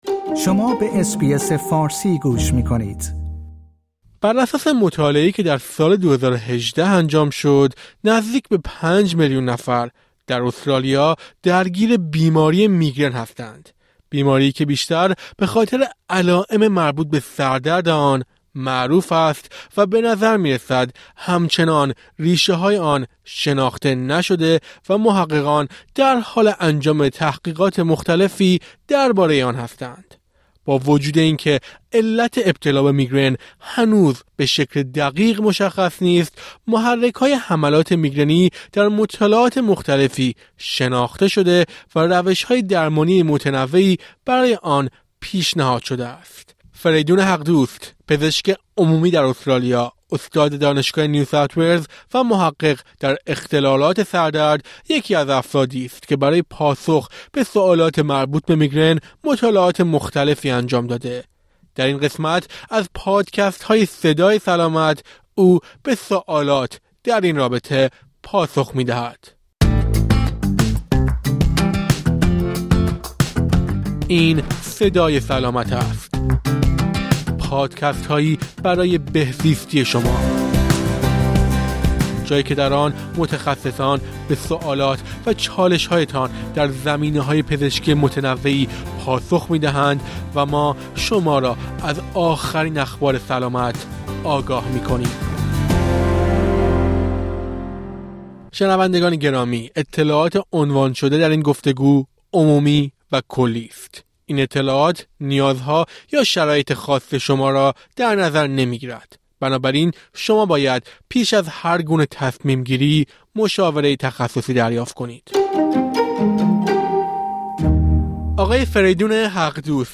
او در گفت‌وگو با اس‌بی‌اس فارسی به برخی سوالات درباره میگرن و به خصوص سردرد پاسخ می‌دهد.